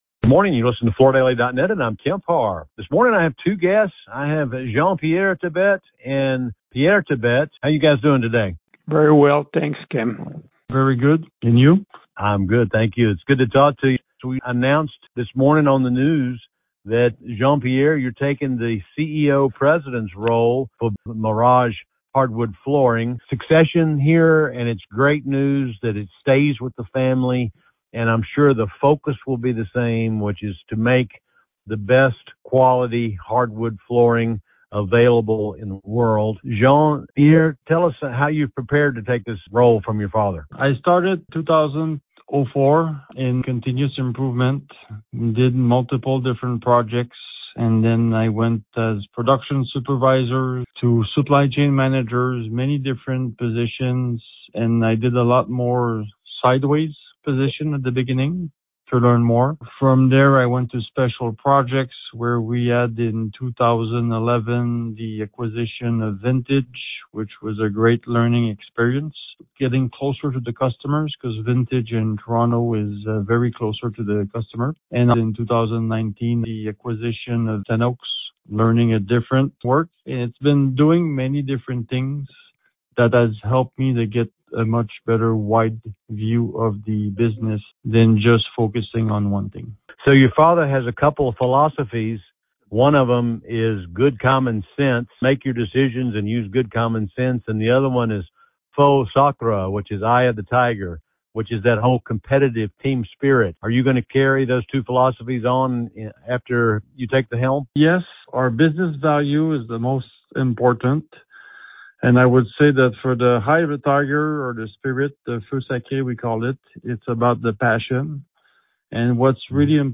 Listen to the interview to hear more about Coverings Central, which will have sessions focusing on social media and how it pertains to the tile industry. Also hear about the new Coverings Installation and Design Awards, recognizing the synergy between design and installation, and another new event, Contractor Days.